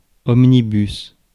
Ääntäminen
IPA : /ˈɒmniˌbʌs/